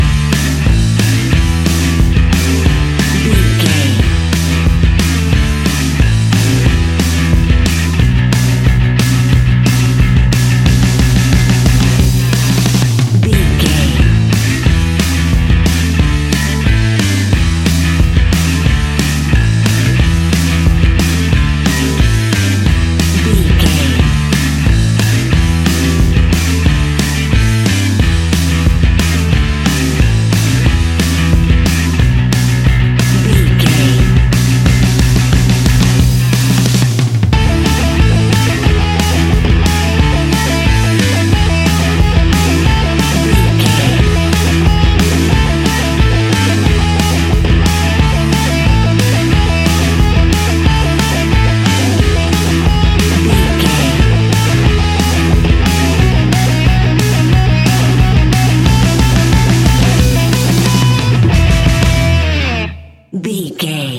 Uplifting
Ionian/Major
hard rock
punk metal
Rock Bass
heavy drums
distorted guitars
hammond organ